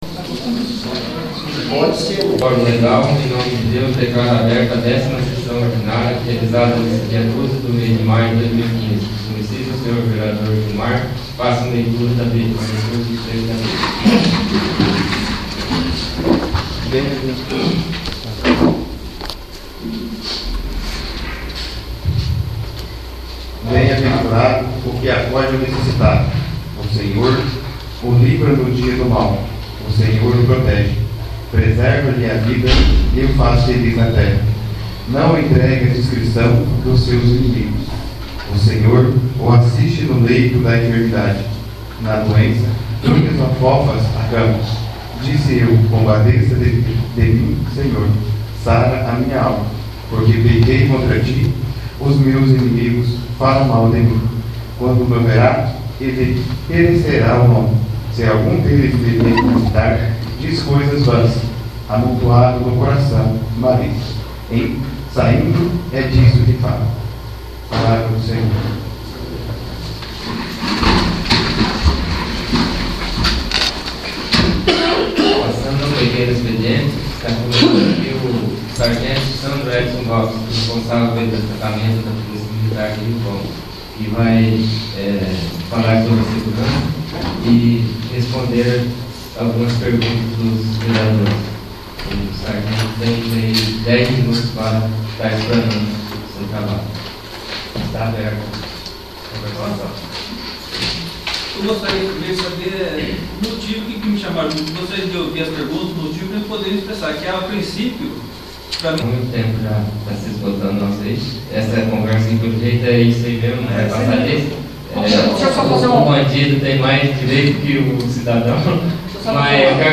10º. Sessão Ordinária